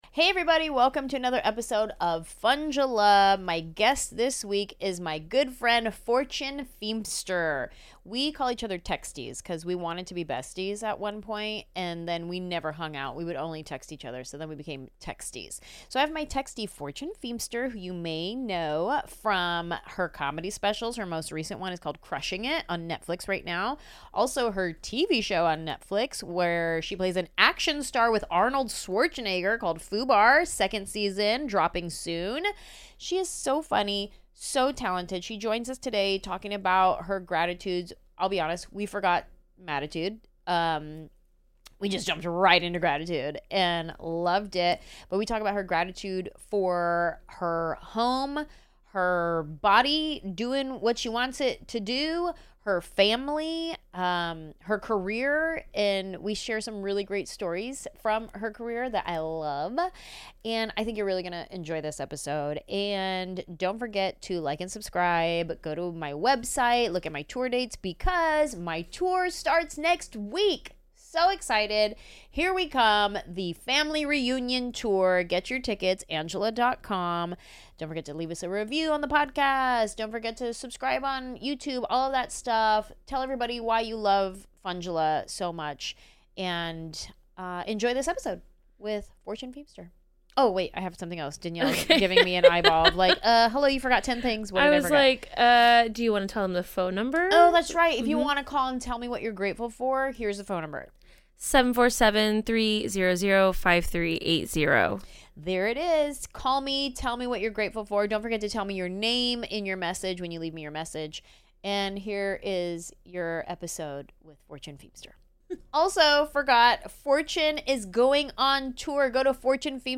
Fortune and Anjelah, aka Text Besties, aka Texties - the only way to stay in touch when you're both touring comedians! Now they're in the Funjelah studio catching up things like going to the club when they'd rather be at a nice dinner, what it was like when Fortune worked with Arnold Schwarzenegger, and gratitude for a safe home, being alive, and family.